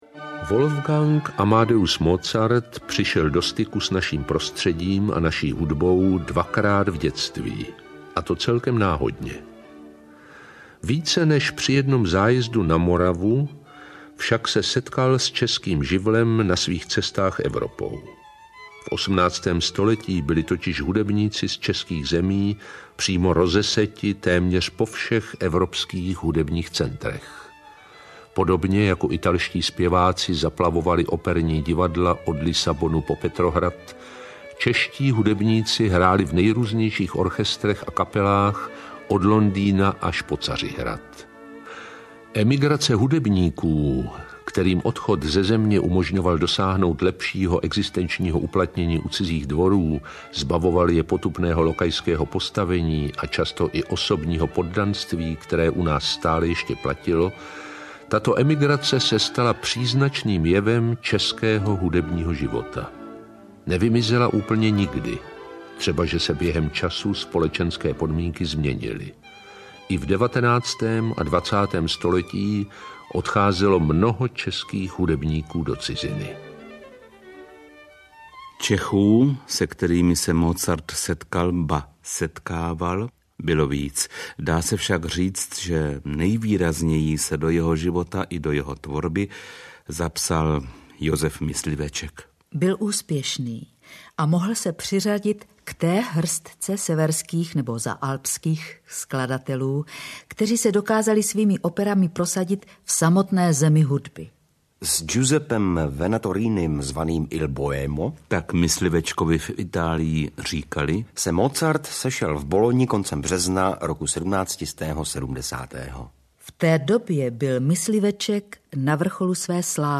Toulky českou minulostí 601 - 650 audiokniha
Ukázka z knihy